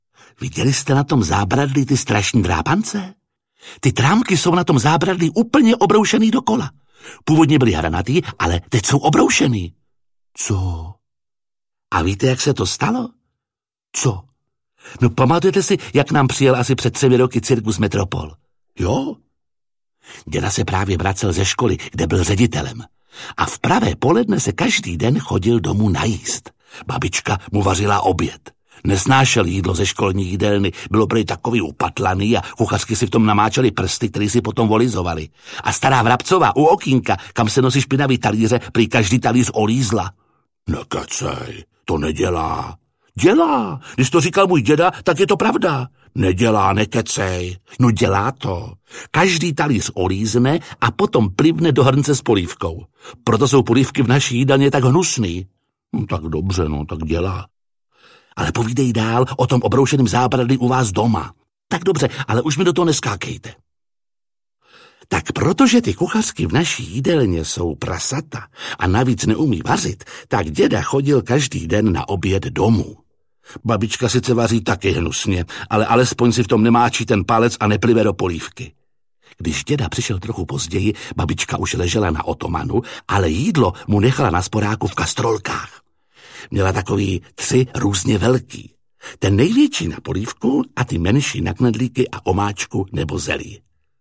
Poslední promítač ze Sudet audiokniha
Ukázka z knihy
• InterpretJiří Lábus
posledni-promitac-ze-sudet-audiokniha